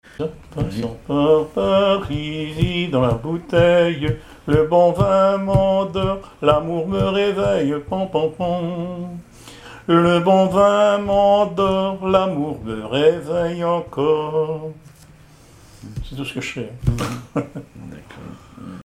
Genre laisse
Témoignages sur le cycle calendaire et des extraits de chansons maritimes
Pièce musicale inédite